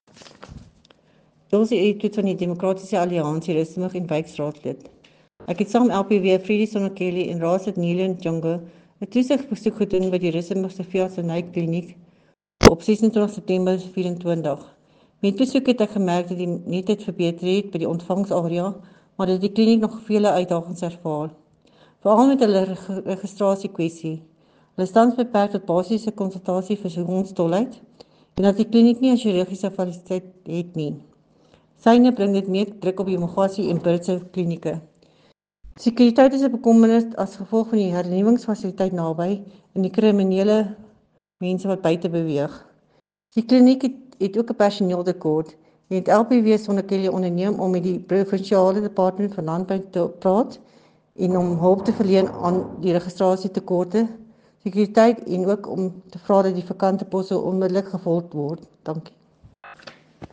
Note to broadcasters: Please find linked soundbites in